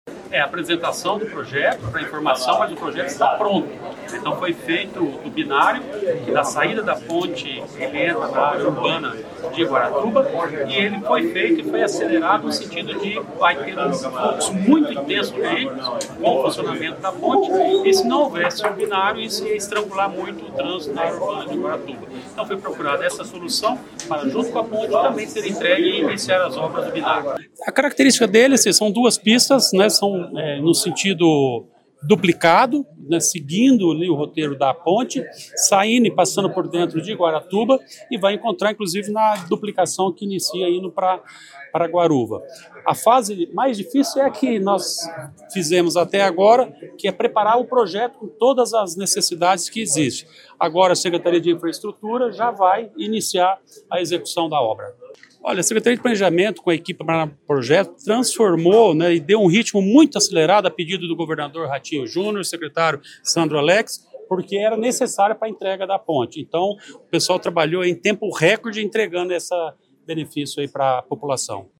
Sonora do secretário Estadual do Planejamento, Ulisses Maia, sobre o novo binário de Guaratuba